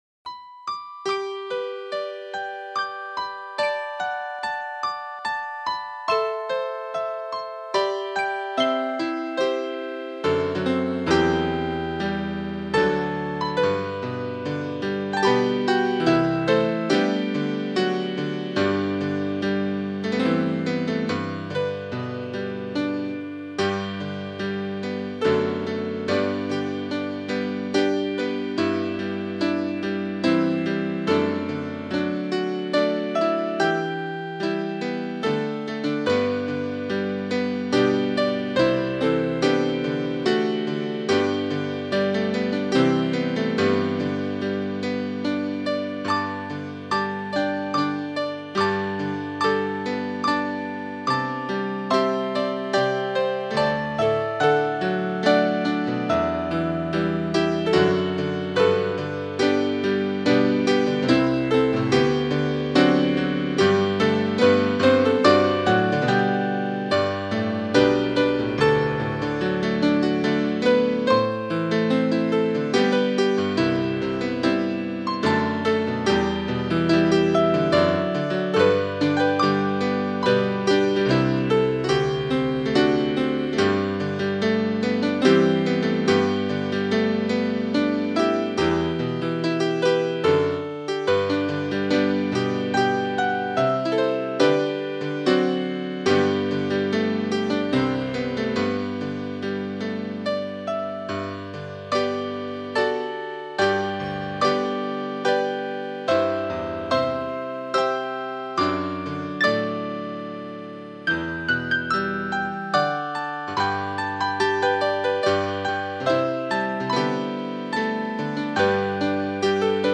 Cor mixt
CUVÂNT MINUNAT (negativ)